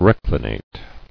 [rec·li·nate]